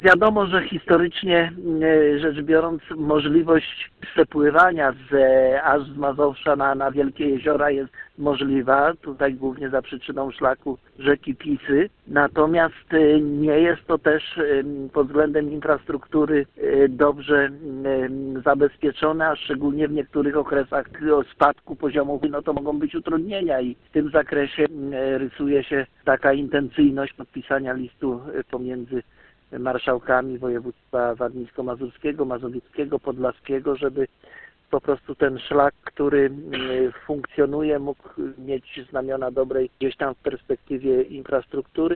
– Jest to historyczny szlak, obecnie trochę zaniedbany inwestycyjnie – mówi marszałek województwa warmińsko-mazurskiego Gustaw Marek Brzezin.